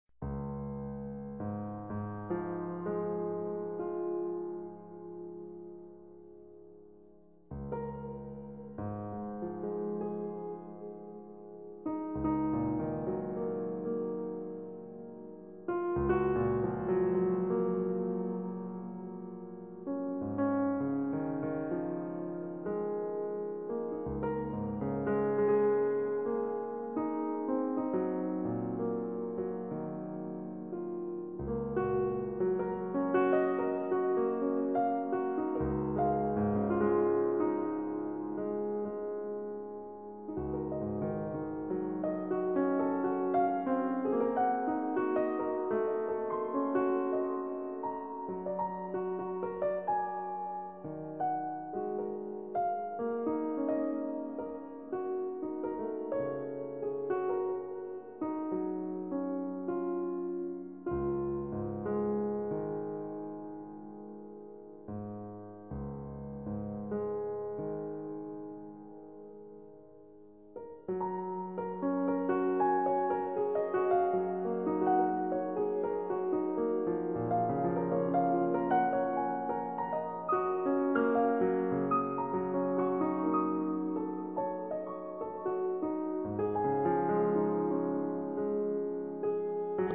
piano collection